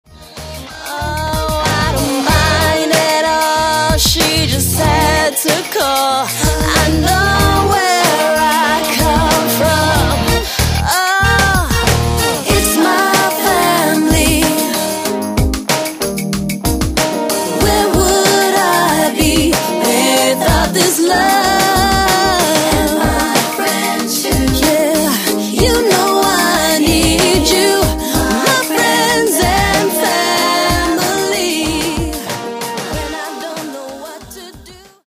pop/r&b
contemporary sounds of guitar-based pop rock with
smooth and sexy r&b rhythms and reggae-tinged